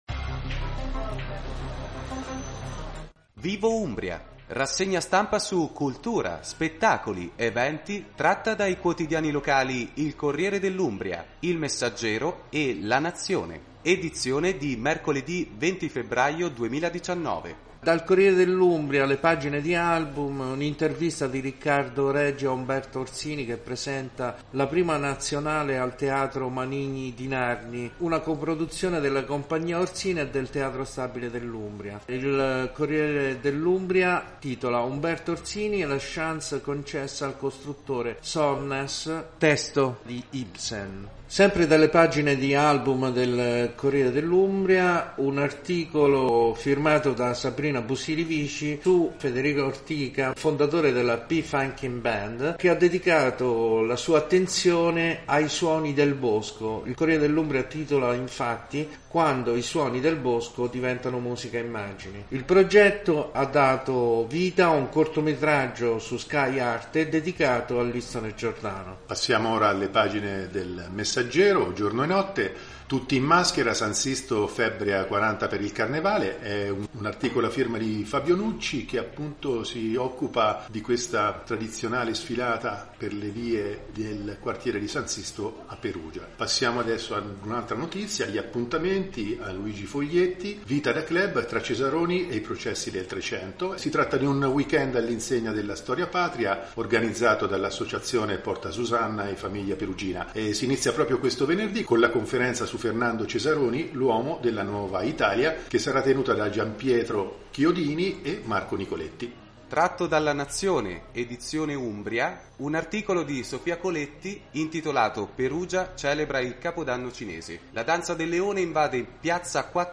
20 Febbraio 2019 Rassegna Stampa - Vivo Umbria